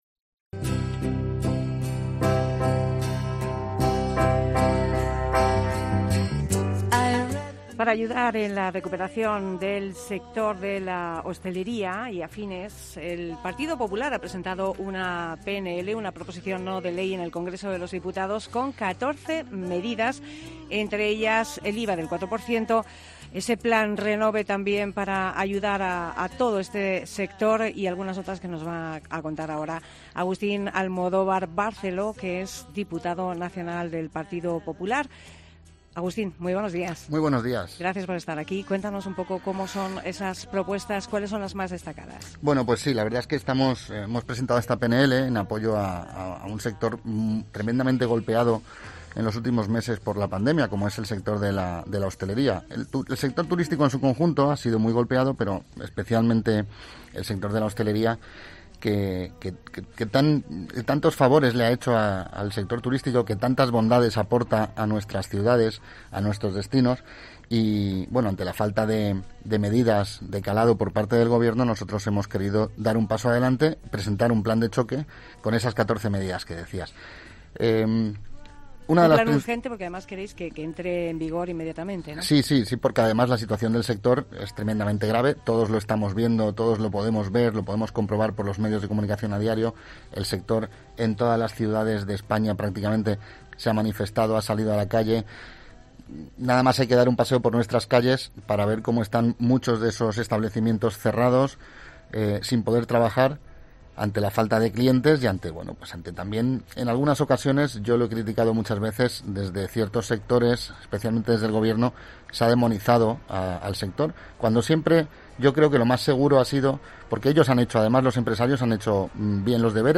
Entrevista a Agustín Almodóvar